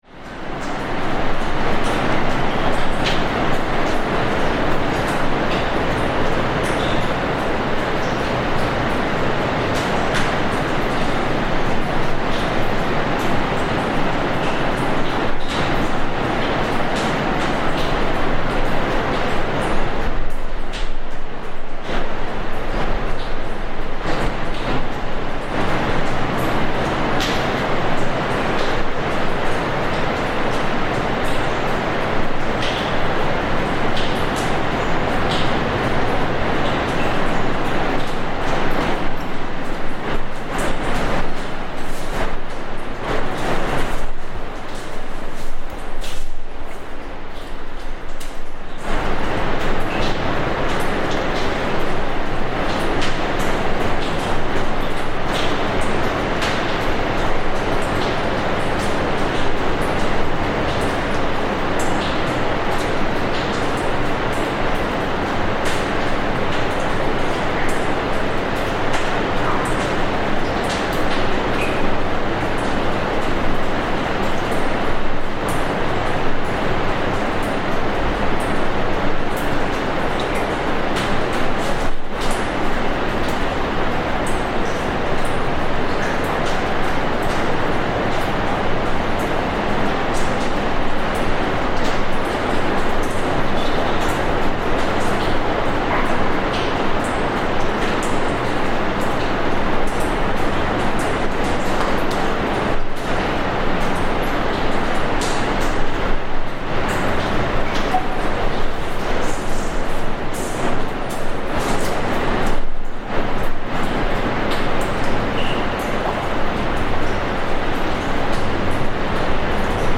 Dripping water inside a sea cave
Inside a sea cave on a Cornish beach, recording dripping waves on different surfaces and at different distances, onto rock, into small puddles and directly onto sand, while the roar of the sea waves continues outside the cave.
Recorded in Mawgan Porth, Cornwall, England by Cities and Memory, April 2025.